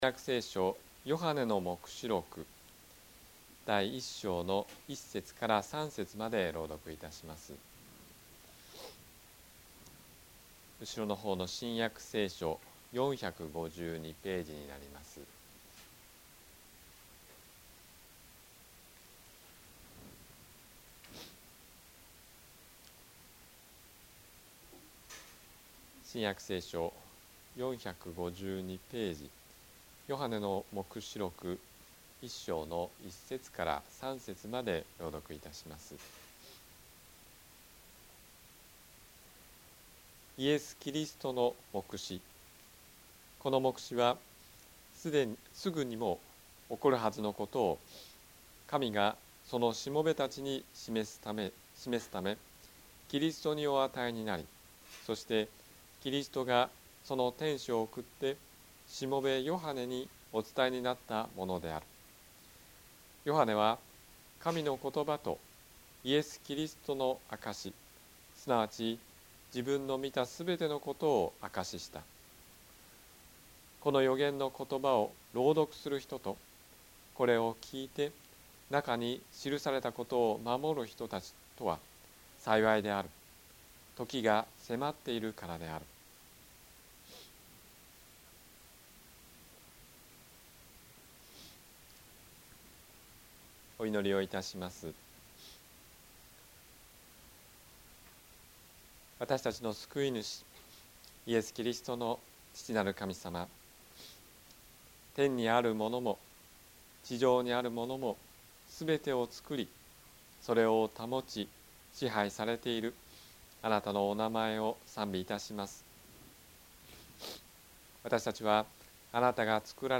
日曜 夕方の礼拝
説教